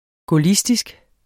Udtale [ gɔˈlisdisg ]